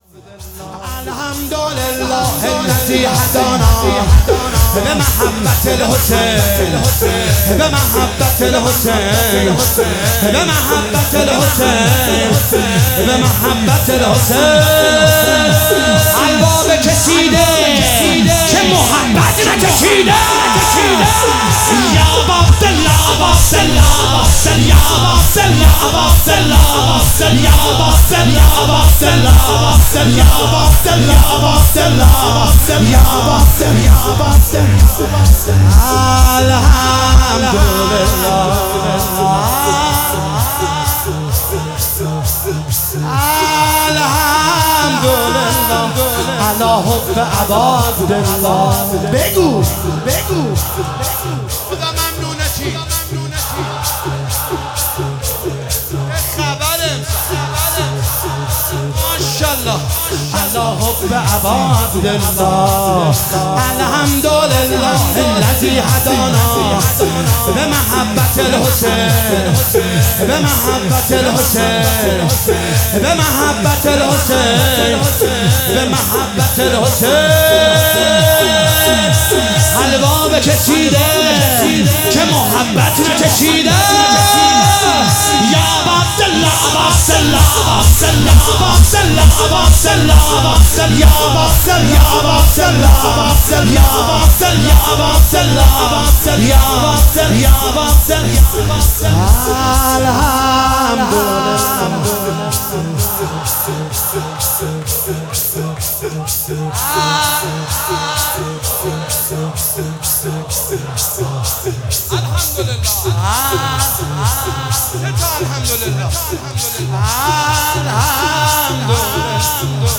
شور شب اول محرم 1403